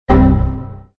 Fx Error Sound Button - Free Download & Play